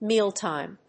音節méal・tìme 発音記号・読み方
/ˈmiˌltaɪm(米国英語), ˈmi:ˌltaɪm(英国英語)/